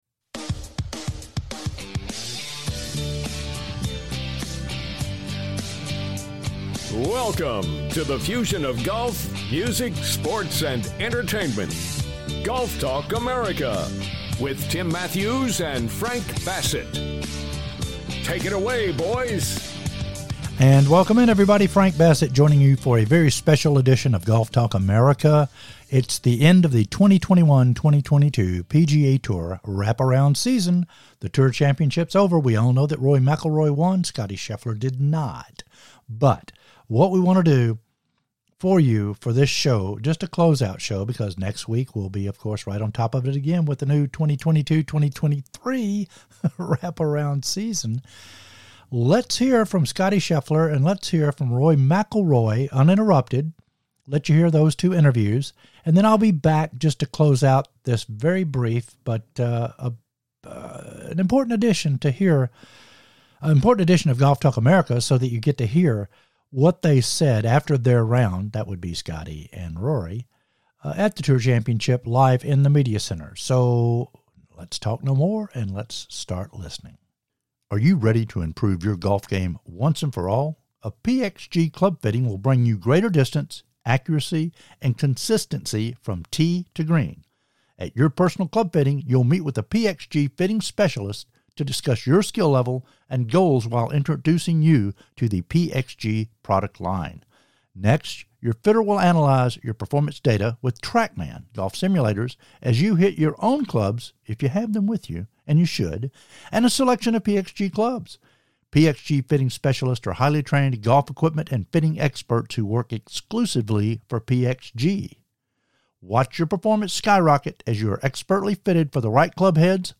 interviews from the 2022 PGA TOUR Championship